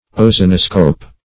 Search Result for " ozonoscope" : The Collaborative International Dictionary of English v.0.48: Ozonoscope \O*zo"no*scope\, n. [Ozone + -scope.]